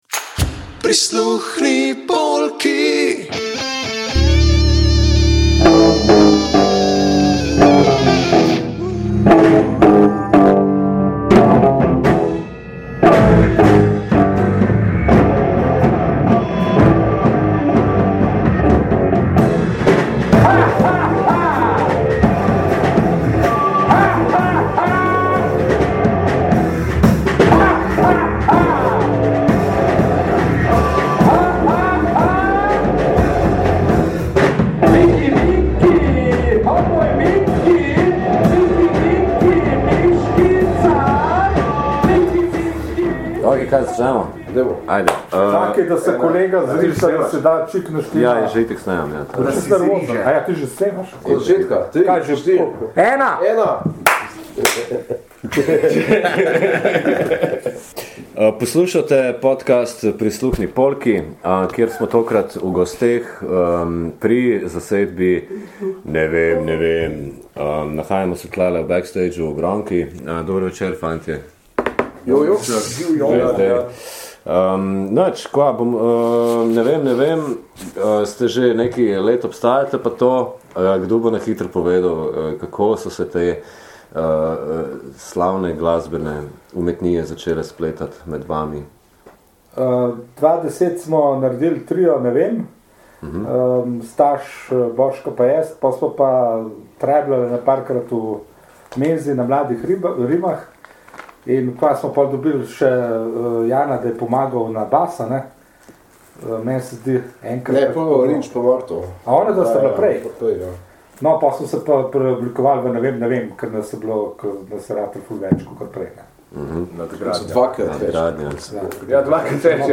Nevem Nevem – nojzersko pesniški klaster – spoznavamo v njihovem vadbenem prostoru v klubu Gromka na Metelkovi. Gostobesedni in hudomušni fantje (ki se jim je pridružila še ena, sicer manjkajoča punca) povedo, kako band v delno novi kadrovski in zatorej tudi zvočni sliki deluje v tem desetletju, koliko trenirajo, kaj planirajo in koliko novih komadov imajo za bregom.
Uvodoma poslušamo njihovo novo stvaritev (ki smo ji pozabili ime), bo pa skoraj gotovo slišana na sledečih eventih: